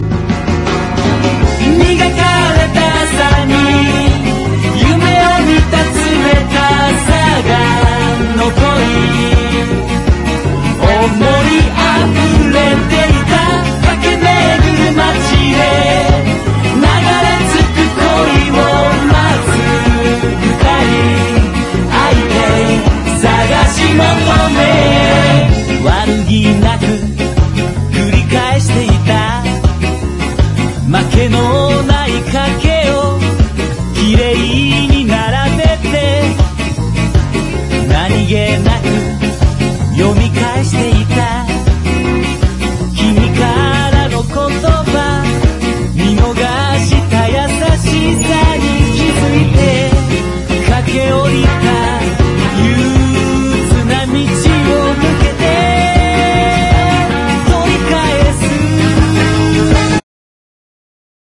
MIXTURE / JAPANESE HIP HOP / JAPANESE PUNK
国産ミクスチャー・ロック！